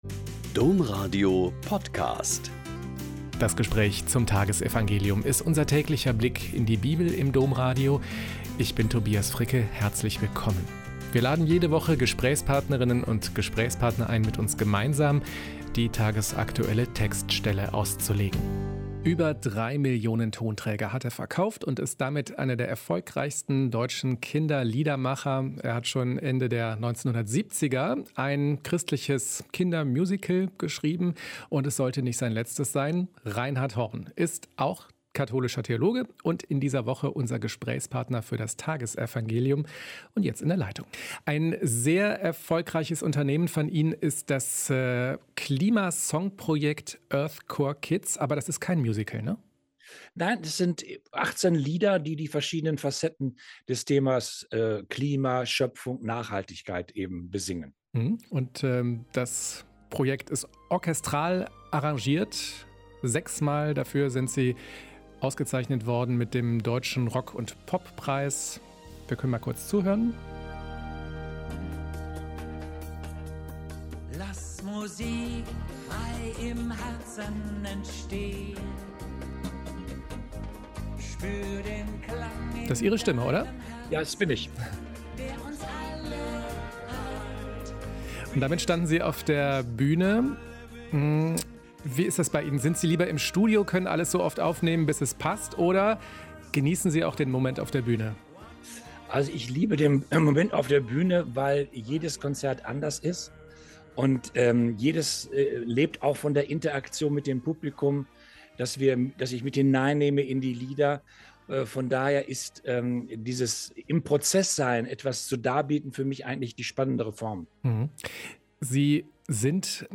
Joh 5,31-47 - Gespräch